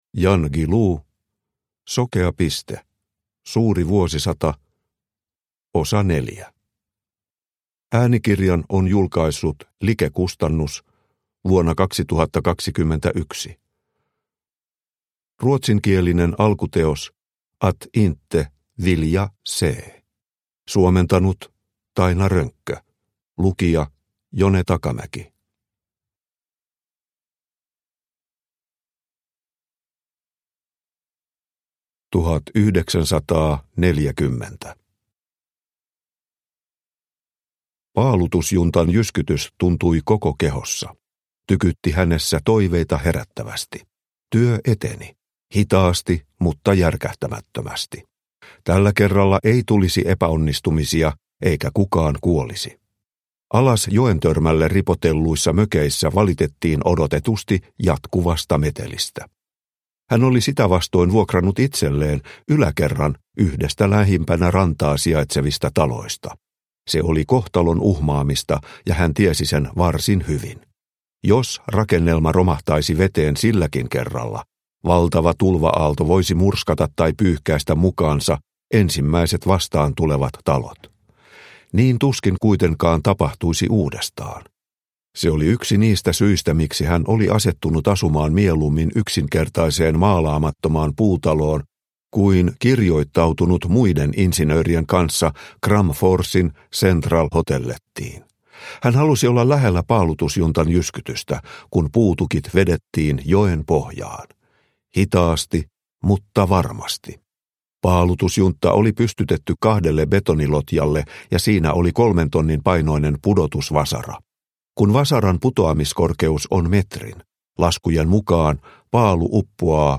Sokea piste – Ljudbok – Laddas ner